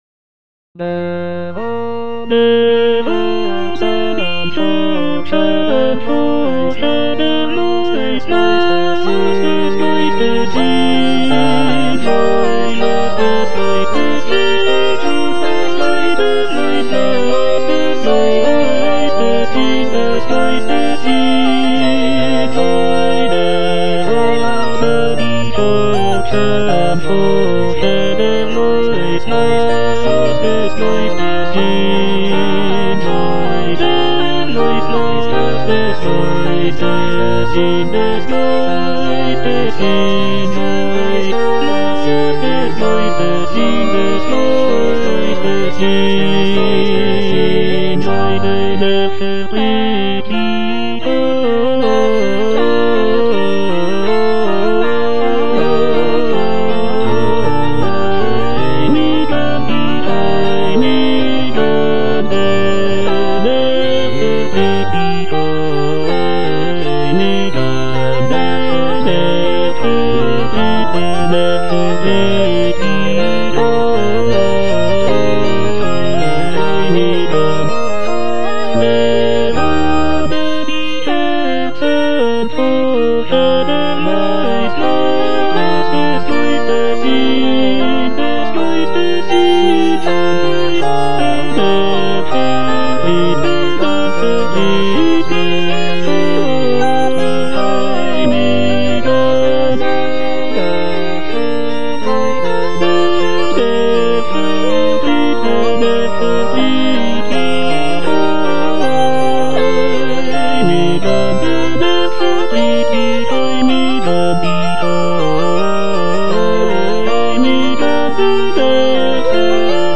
J.S. BACH - DER GEIST HILFT UNSER SCHWACHHEIT AUF BWV226 Der aber die Herzen forschet - Tenor (Emphasised voice and other voices) Ads stop: auto-stop
It is structured in seven parts for double choir, showcasing Bach's mastery of counterpoint and harmonic complexity.